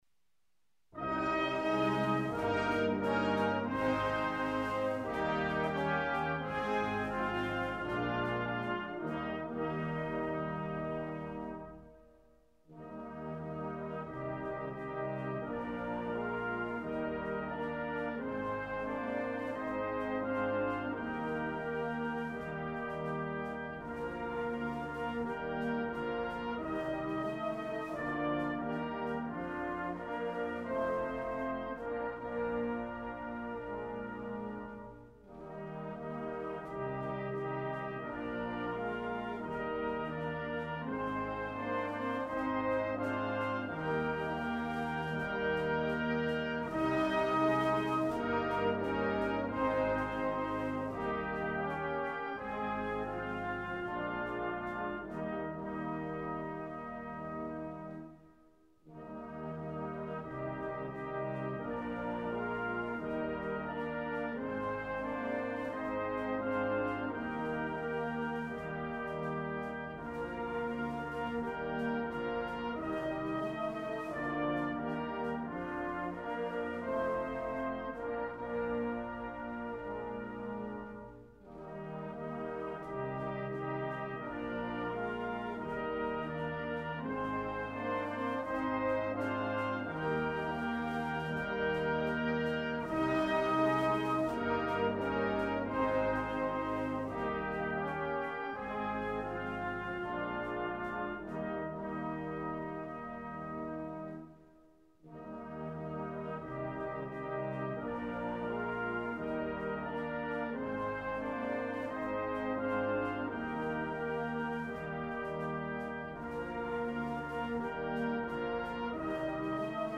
Snare Drum
Band Accomp